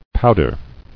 [pow·der]